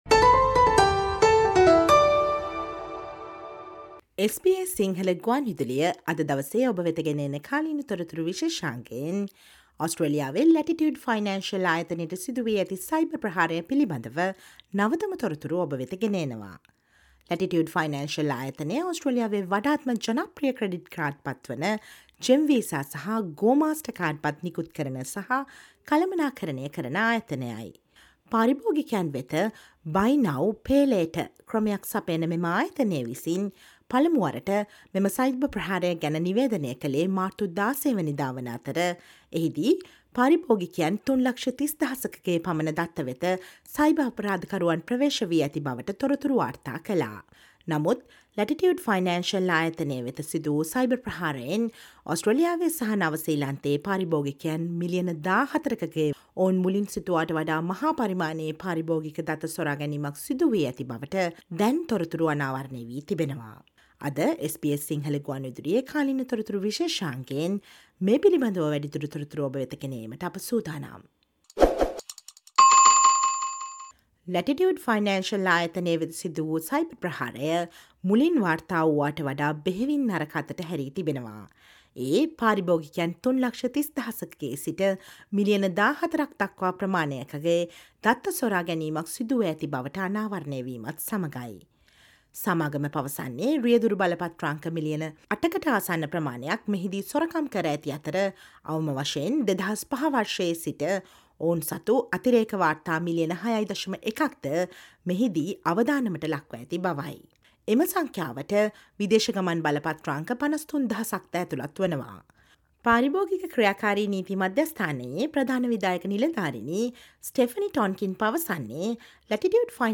Listen to the SBS Sinhala radio current affair feature on the latest updates of the cyber attack to Latitude financial with customer data stolen from around 14 million of its customers.